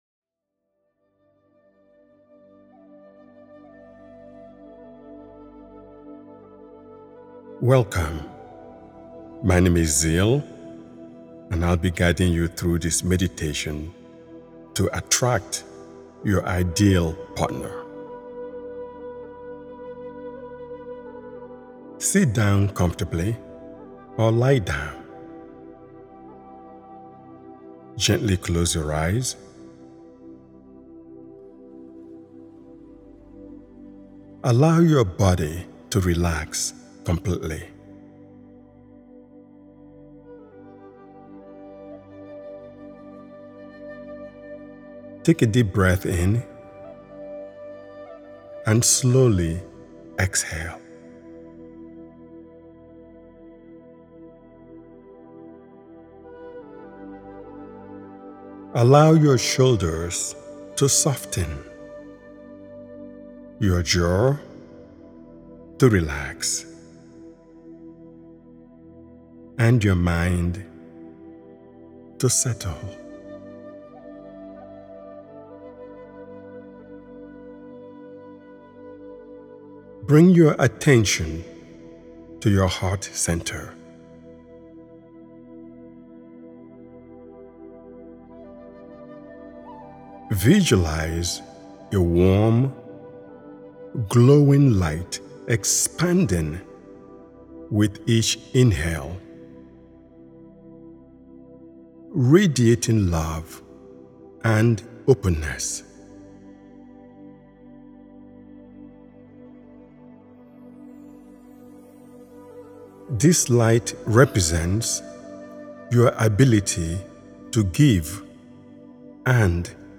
Manifest Love: Attract Your Ideal Partner is a heartfelt and empowering guided meditation created to help you open your heart, strengthen self-love, and align your inner world with the experience of meaningful, fulfilling connection.